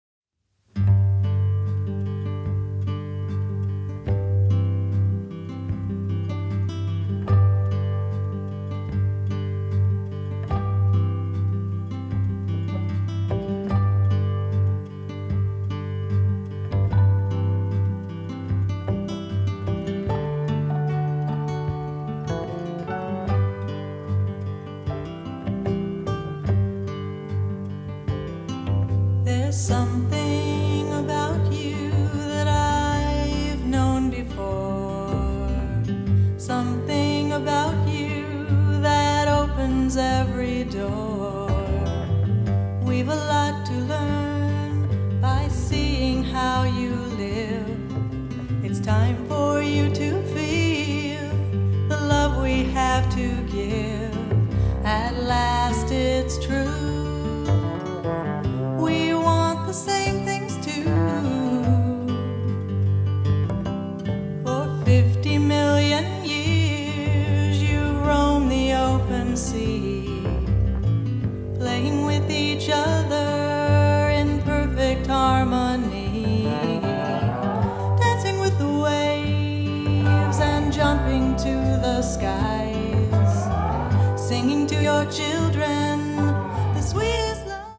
a gentle, rhythmic main theme